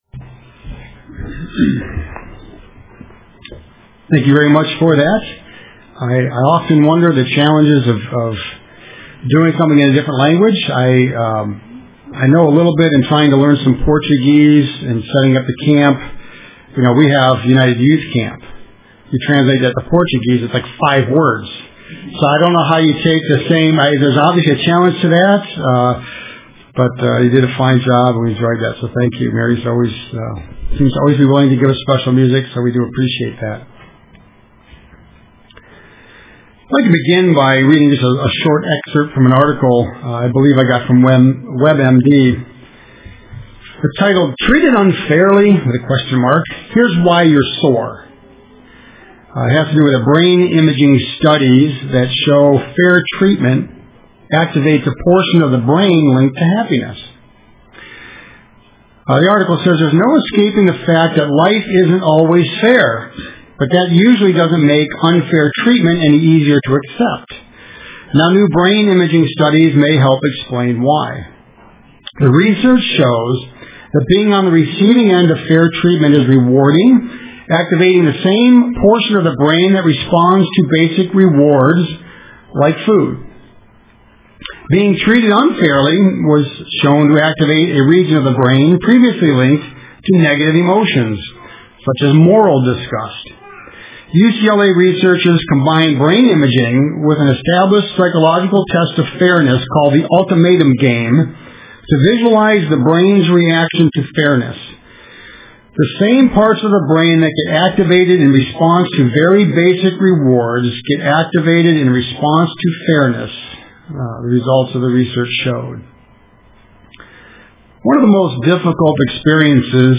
Print Biblical Principles of Conflict Issues UCG Sermon Studying the bible?